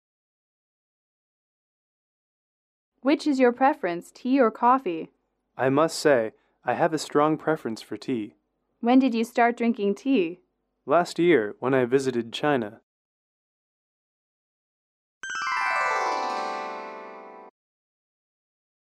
英语口语情景短对话18-1：中国与茶(MP3)